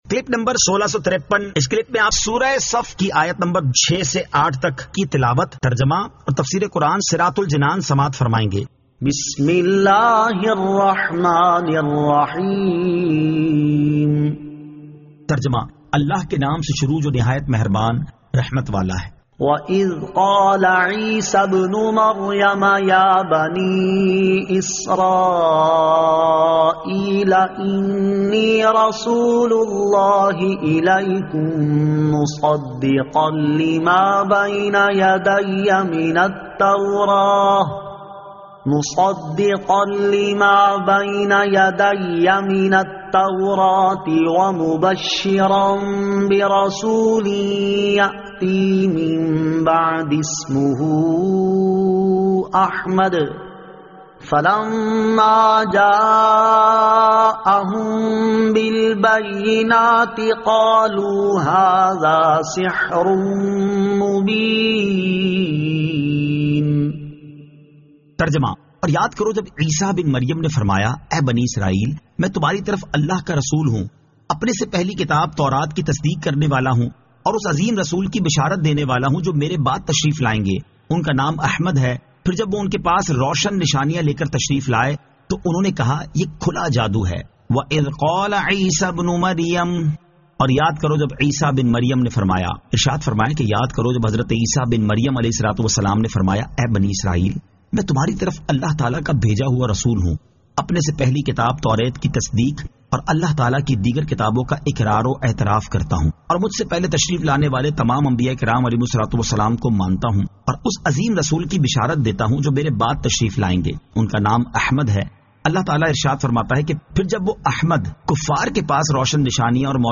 Surah As-Saf 06 To 08 Tilawat , Tarjama , Tafseer